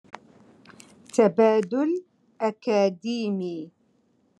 Moroccan Dialect- Rotation Three- Lesson Five